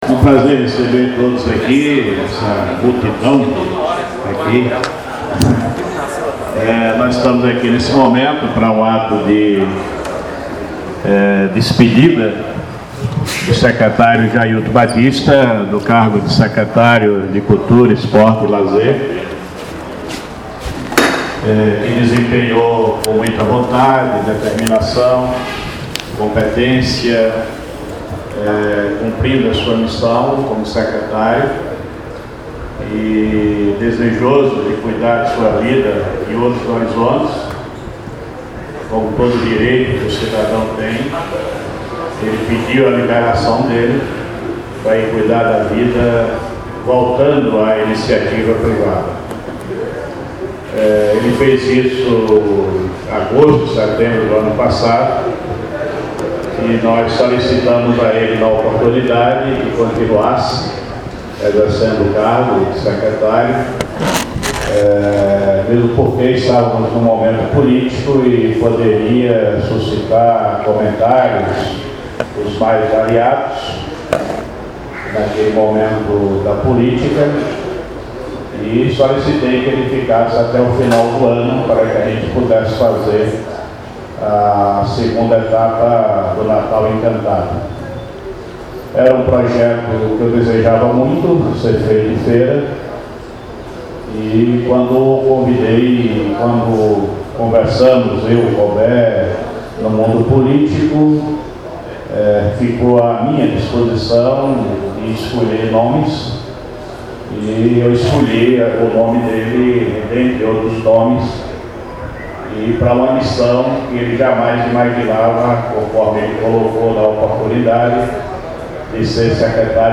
O trabalho do ex-secretário de Cultura, Esporte e Lazer, Jailton Batista, que ficou pouco mais de dois anos à frente da pasta, foi elogiado pelo prefeito José Ronaldo de Carvalho, durante a solenidade de transmissão do cargo, na tarde desta quarta-feira (21/01/2015), ocorrida no Paço Maria Quitéria, sede do governo local. A solenidade foi acompanhada por secretários, atletas e artistas e pessoas da comunidade.
Declaracoes-de-Jose-Ronaldo-e-Jailton-Batista-durante-evento-de-transmissao-de-cargo.mp3